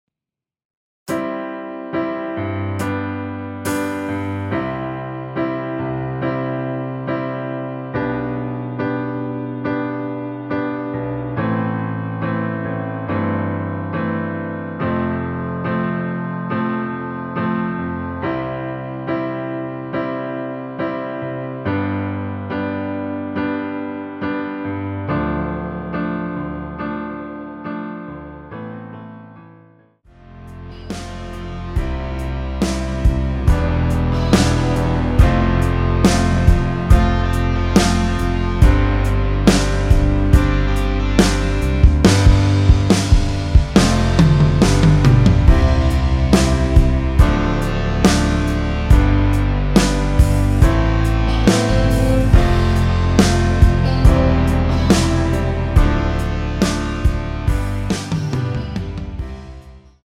노래하기 편하게 전주 1마디 만들어 놓았습니다.(미리듣기 확인)
원키에서(-2)내린 (1절+후렴)으로 진행되는 MR입니다.
앞부분30초, 뒷부분30초씩 편집해서 올려 드리고 있습니다.
중간에 음이 끈어지고 다시 나오는 이유는